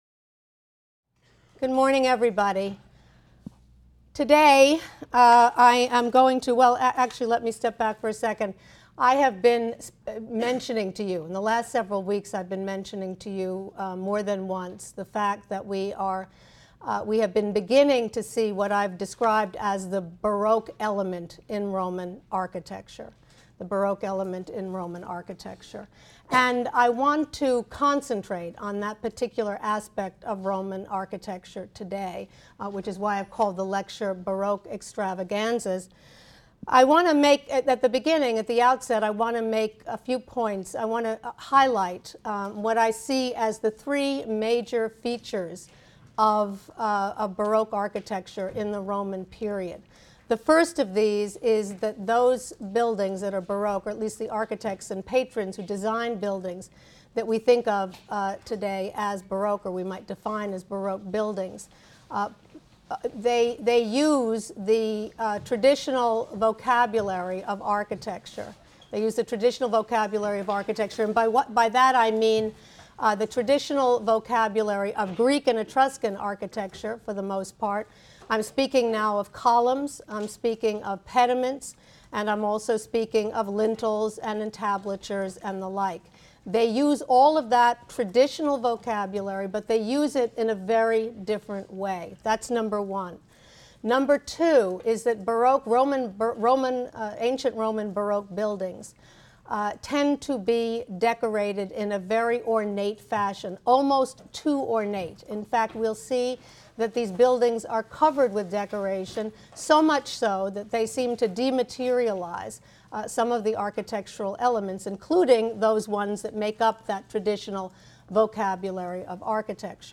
HSAR 252 - Lecture 19 - Baroque Extravaganzas: Rock Tombs, Fountains, and Sanctuaries in Jordan, Lebanon, and Libya | Open Yale Courses